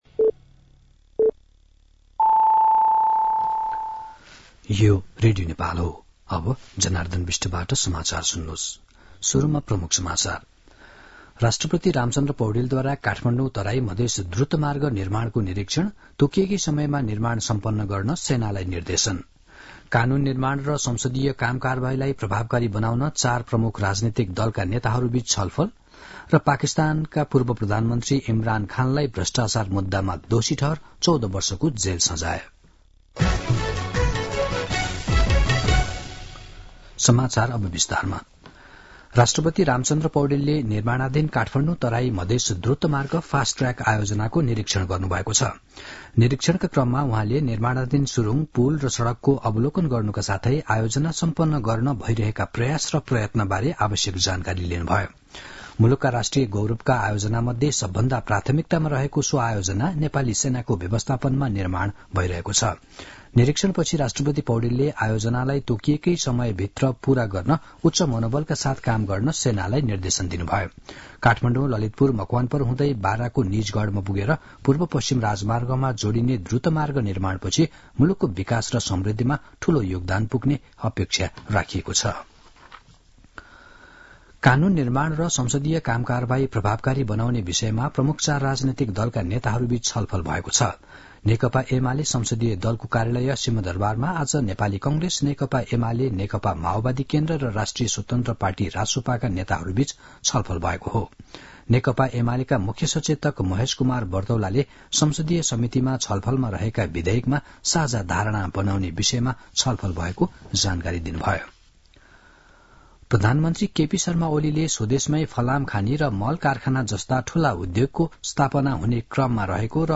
दिउँसो ३ बजेको नेपाली समाचार : ५ माघ , २०८१
3-pm-Nepali-News-10-4.mp3